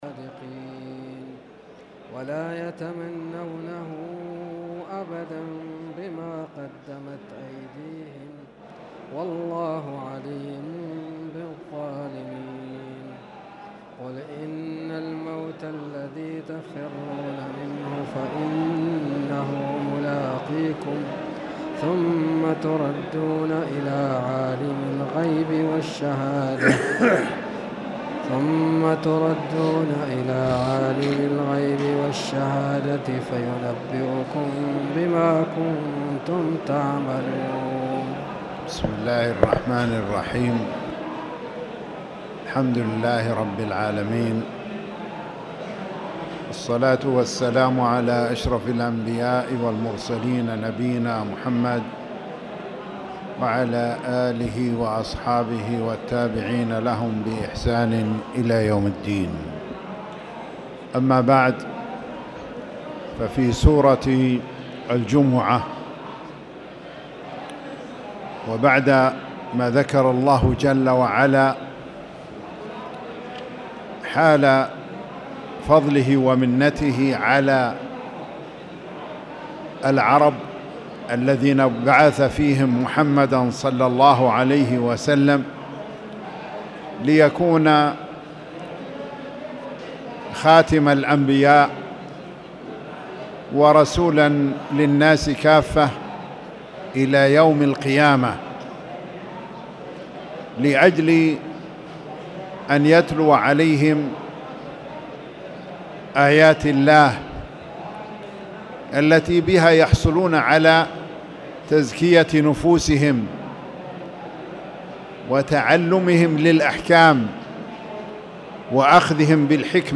تاريخ النشر ١٥ جمادى الأولى ١٤٣٨ هـ المكان: المسجد الحرام الشيخ